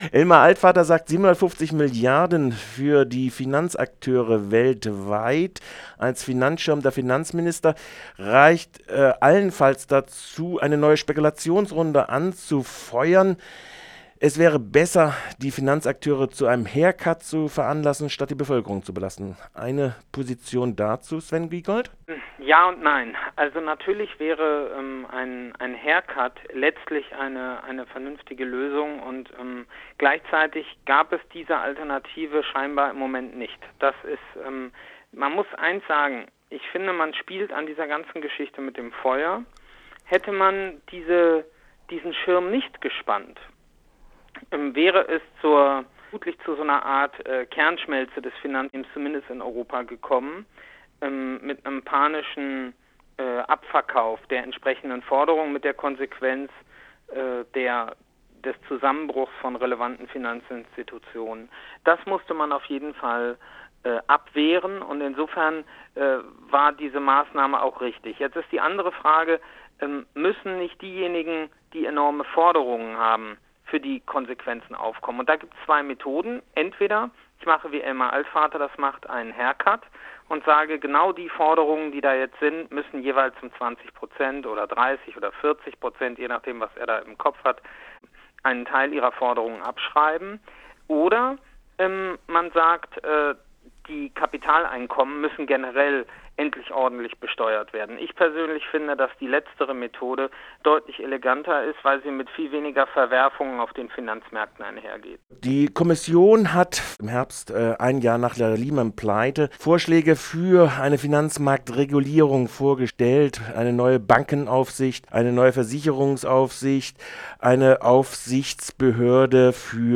Europäische Finanzmarktregulierung? - Sven Giegold im Gespräch